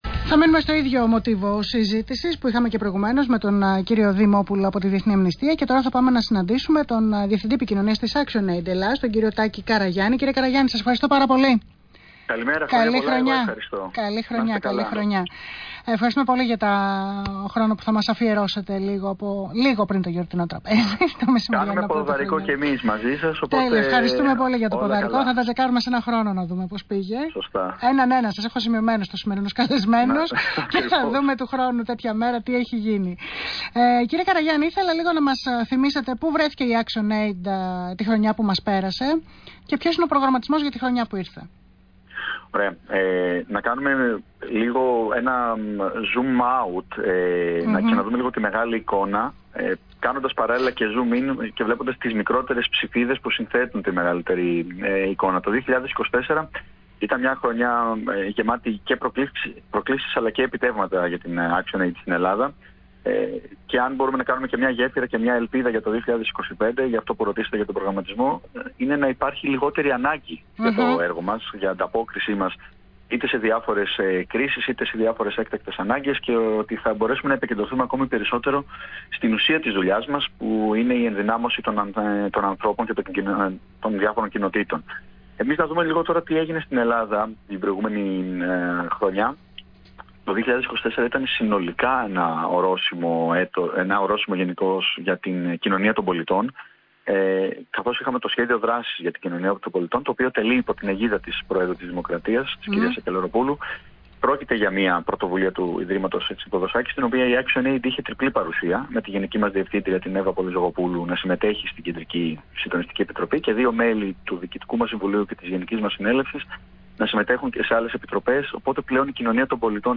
Συνέντευξη στον Αθήνα 9,84: Απολογισμός δράσεων της ActionAid για το 2024